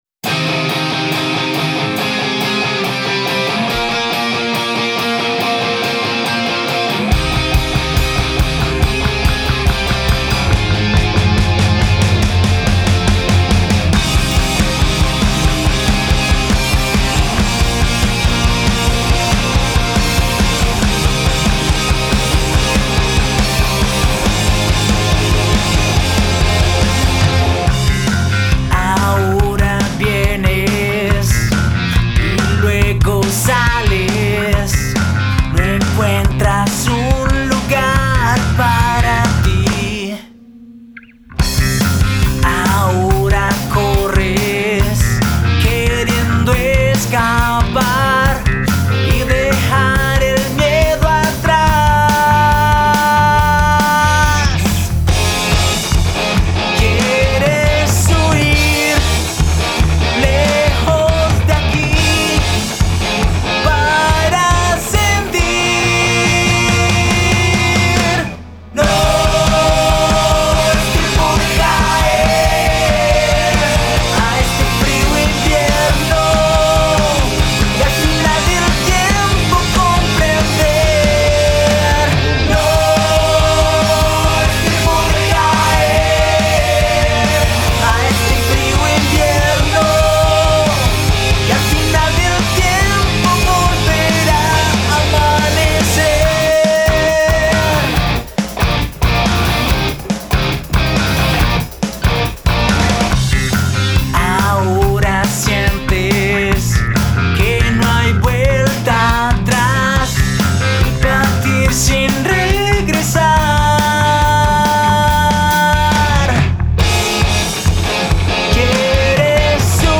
Rock Alternativo